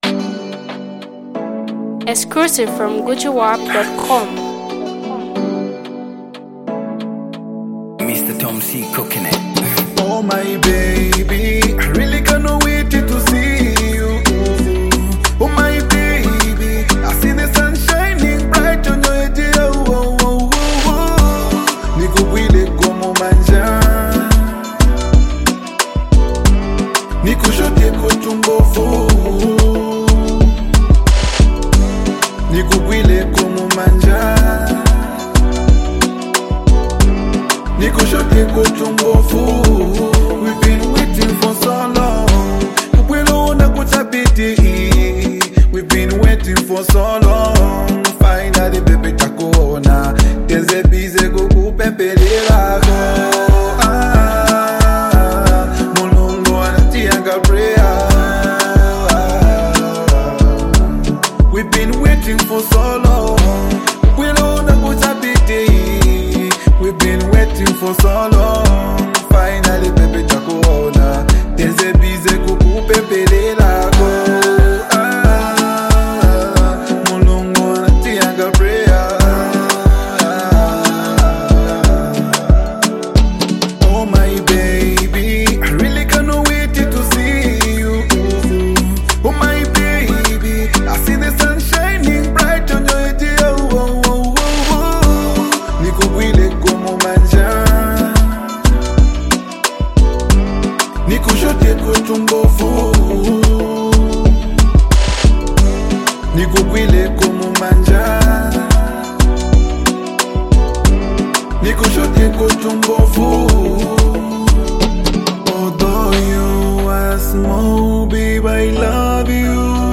Through soulful melodies and evocative lyrics